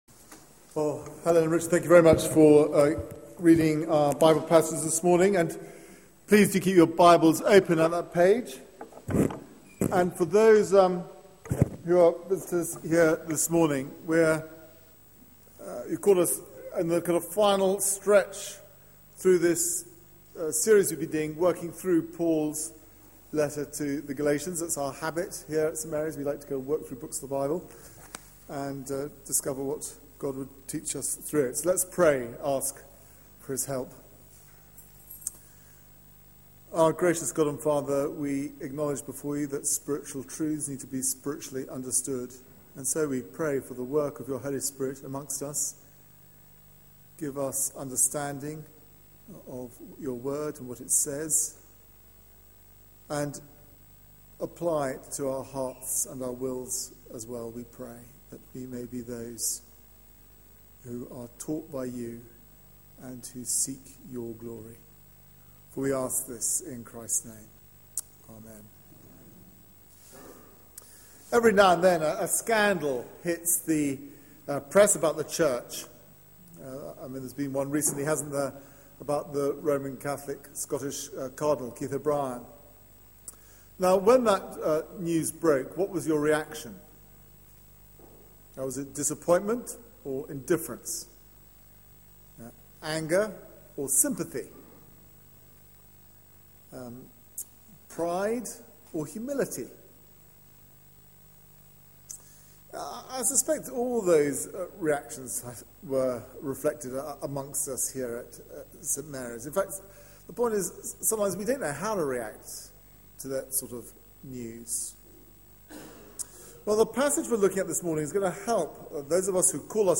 Media for 9:15am Service on Sun 17th Mar 2013 09:15 Speaker